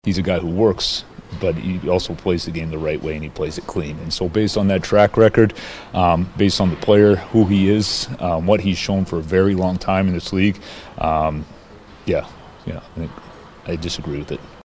Coach Dan Muse says Rust isn’t a dirty player.